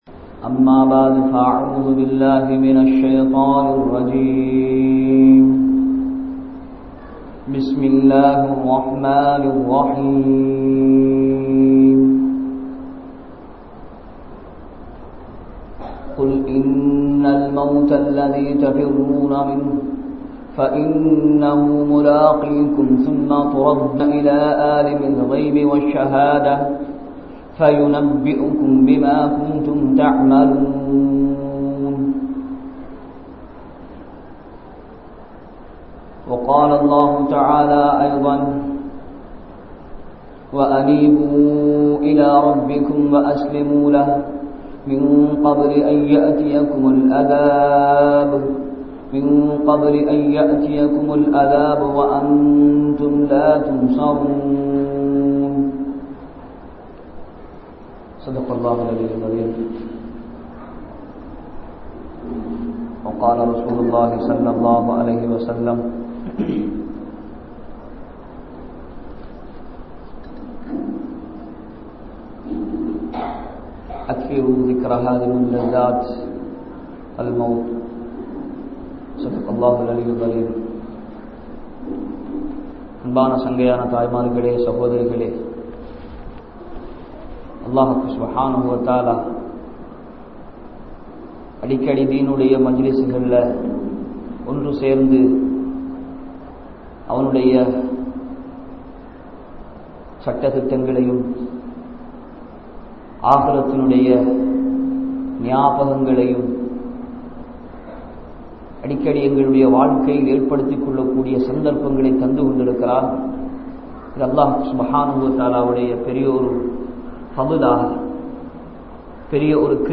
Sakraath & Mouth | Audio Bayans | All Ceylon Muslim Youth Community | Addalaichenai